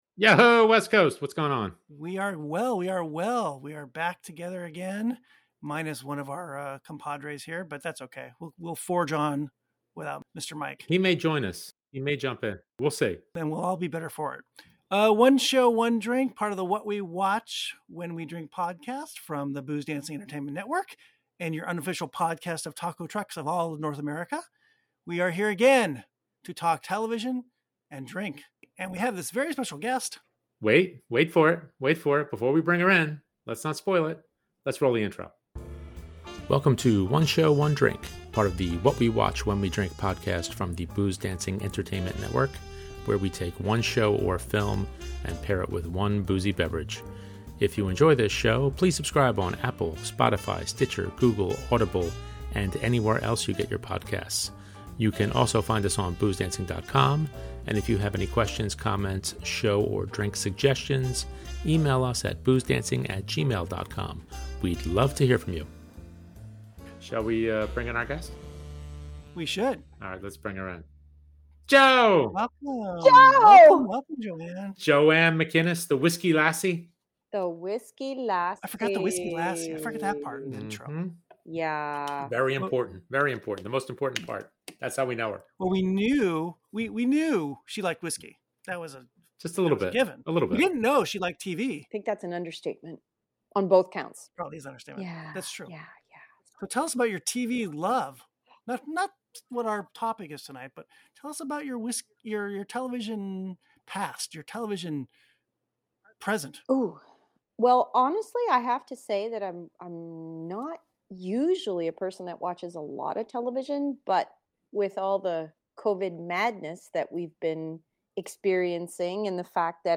Grab a whisky and enjoy the chat!